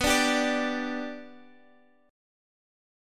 Bm#5 chord